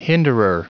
Prononciation du mot hinderer en anglais (fichier audio)
Prononciation du mot : hinderer